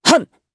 Lucias-Vox_Attack1_jp_b.wav